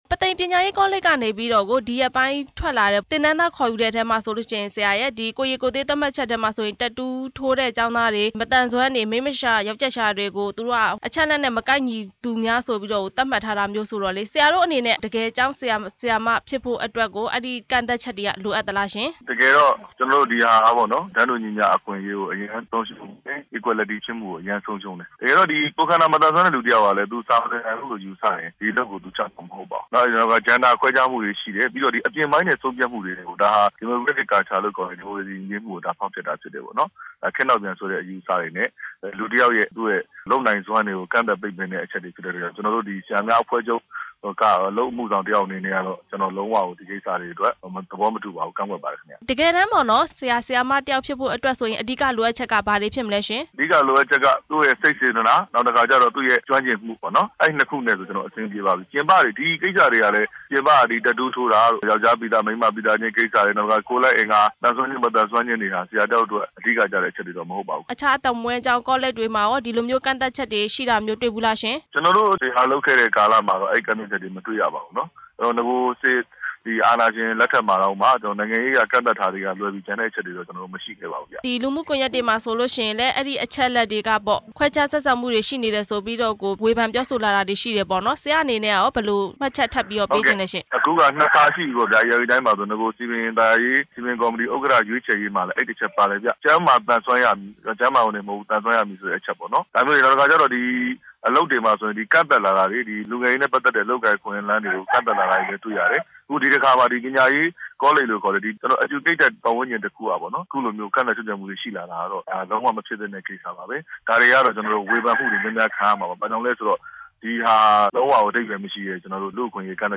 ပုသိမ်ပညာရေးကောလိပ် သင်တန်းသားခေါ်ယူမှု မေးမြန်းချက်